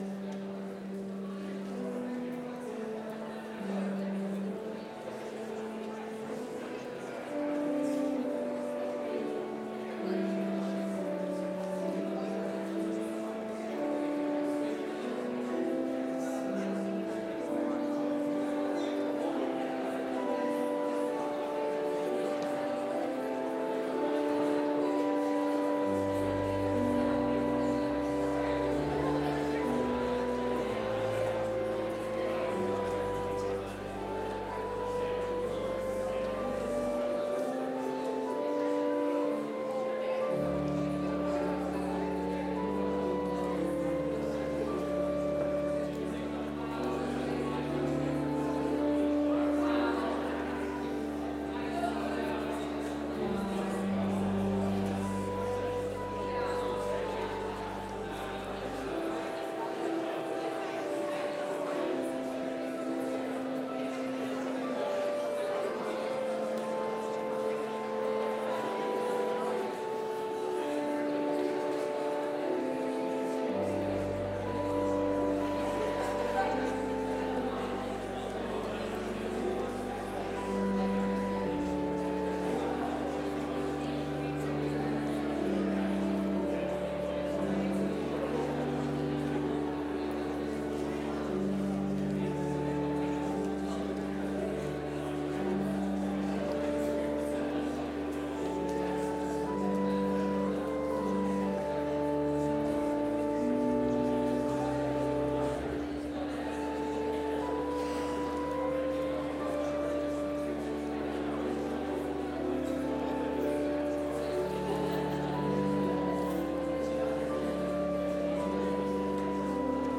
Complete service audio for Chapel - Monday, November 25, 2024